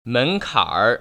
발음 : [ ménkǎn(r) ] 듣기새로고침다른독음 열기/닫기